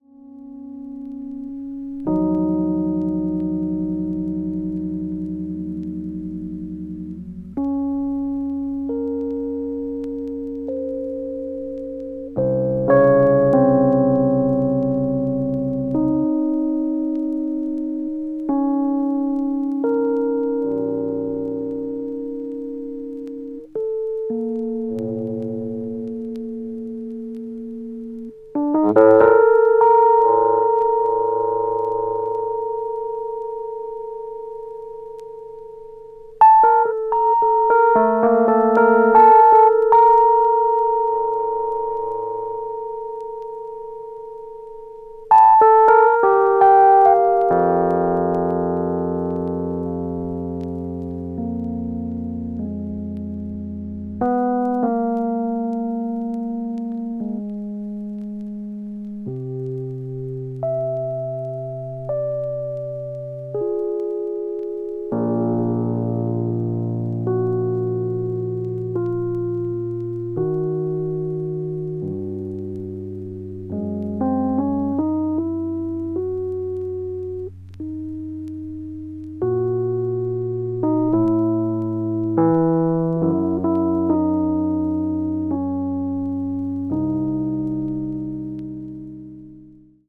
avant-jazz   contemporary jazz   deep jazz   free jazz